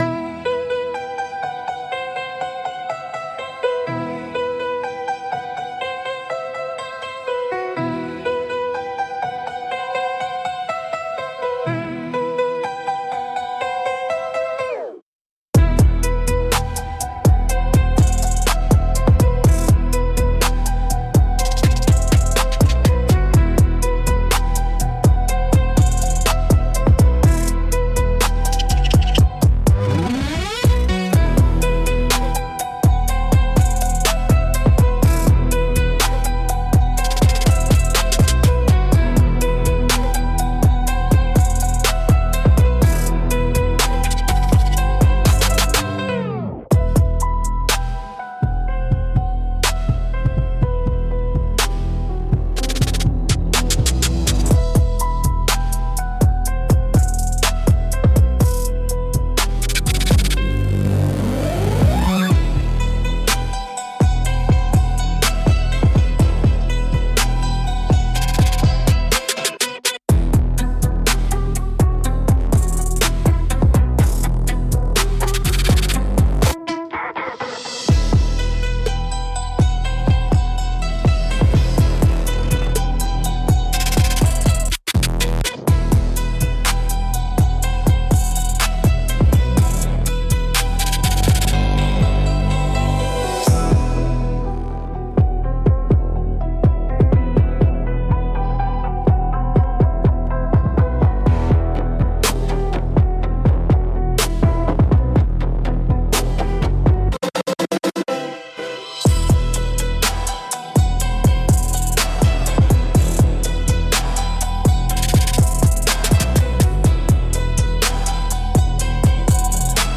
Instrumentals - King of the Underground